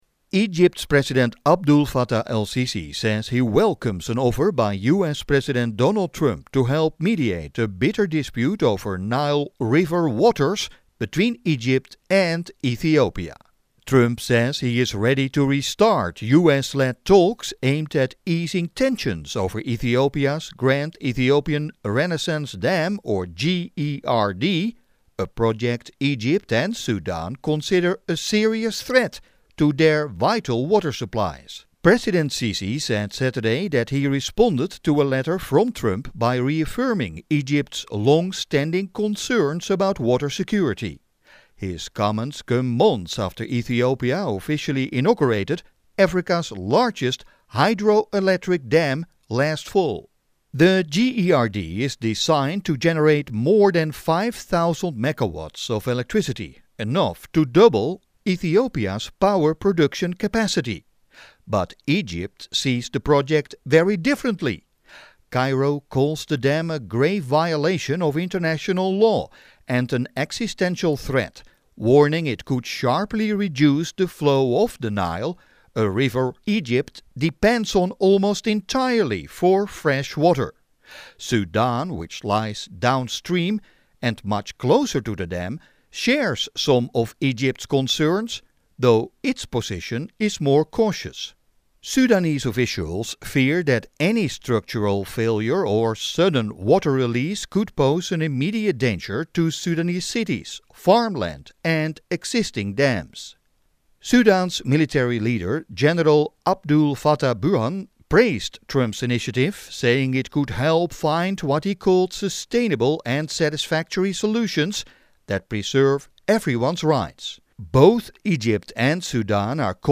Egypt, Sudan Welcome Trump Offer To Mediate Nile Dispute with Ethiopia (Worthy News Radio Analysis)